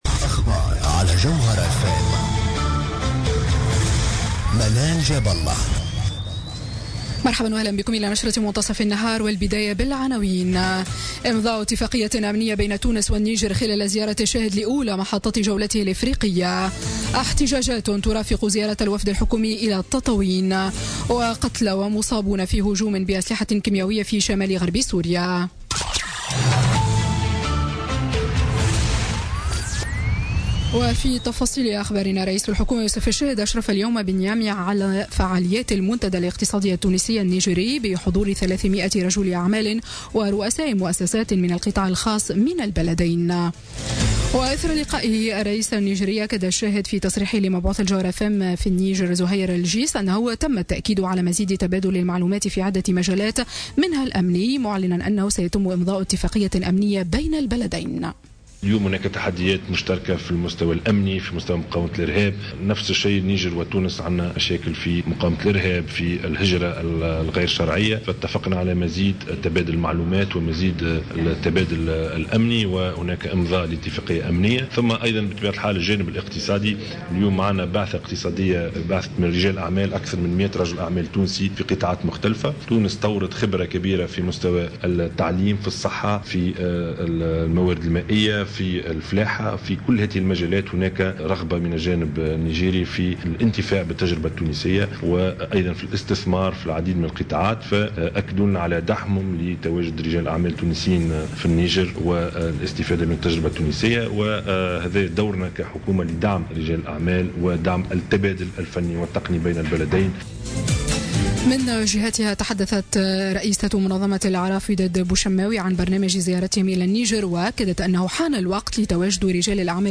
نشرة أخبار منتصف النهار ليوم الثلاثاء 4 أفريل 2017